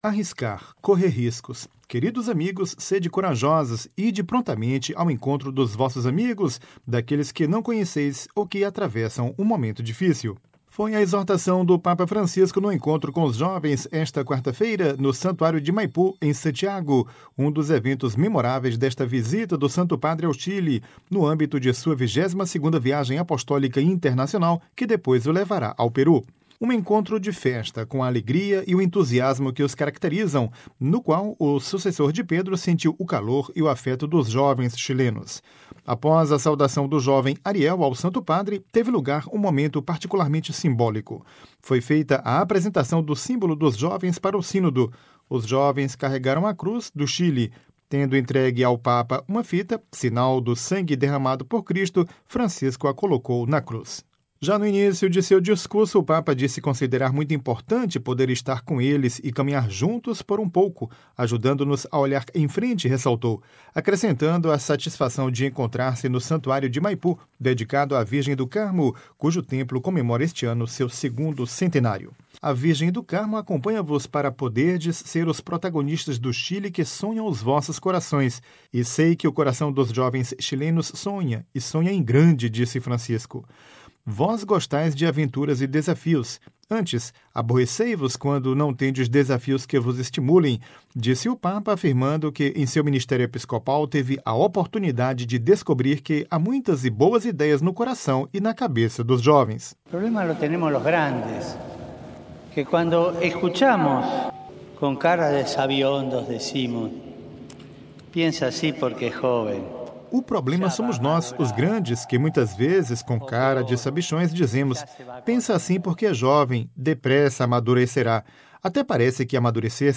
Um encontro de festa, com a alegria e o entusiasmo que os caracterizam, no qual o Sucessor de Pedro sentiu o calor e o afeto dos jovens chilenos.
Foi a exortação do Papa Francisco no encontro com os jovens esta quarta-feira (17/01) no “Santuário de Maipú”, em Santiago, um dos eventos memoráveis desta visita do Santo Padre ao Chile, no âmbito de sua 22ª viagem apostólica internacional, que depois o levará ao Peru.